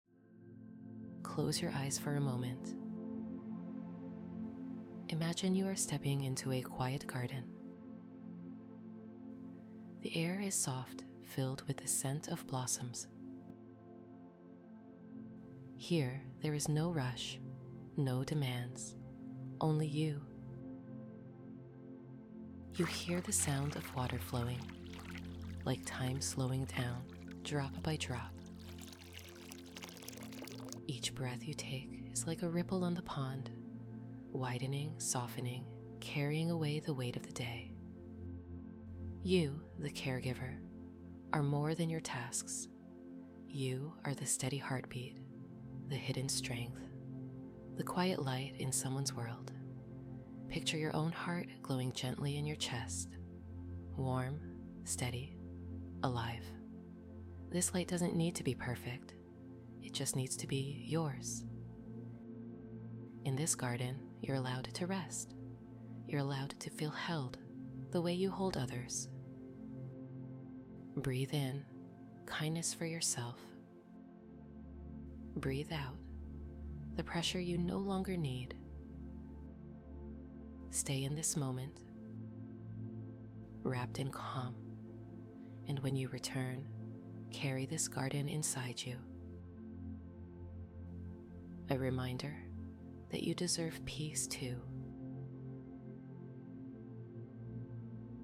This calming meditation helps you reconnect with nature’s quiet, take a deep breath, and return to your caregiving with fresh energy, clarity, and inner peace.